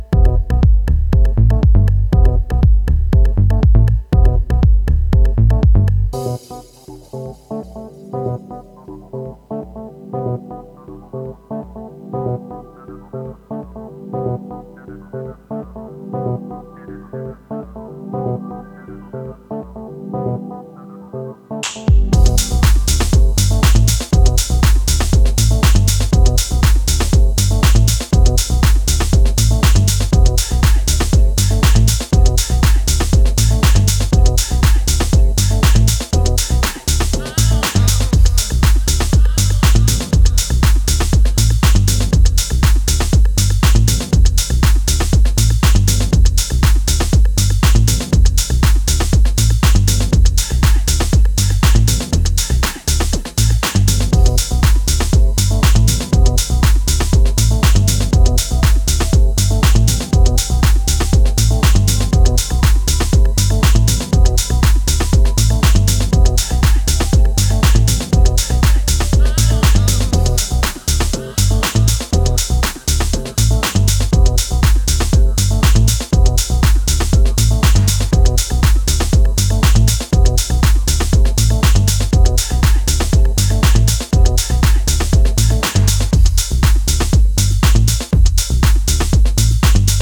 4 stripped down house grooves